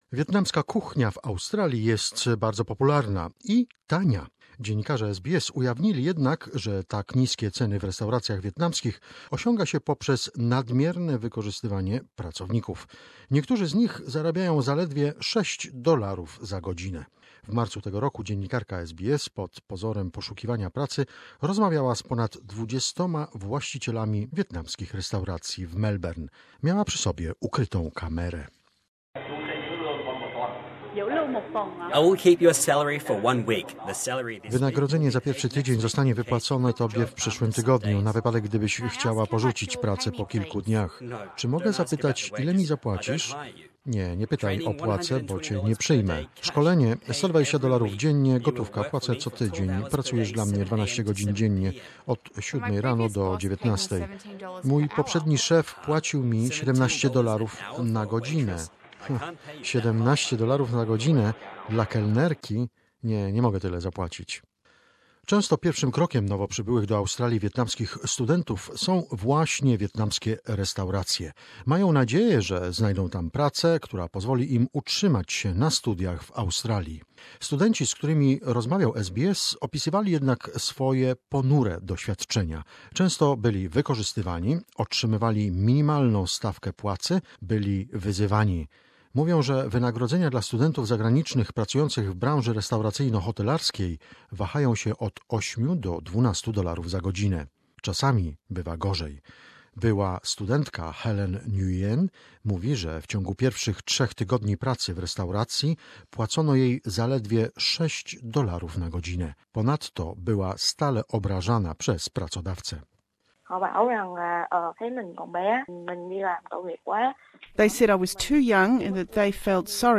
Interview undercover Source: SBS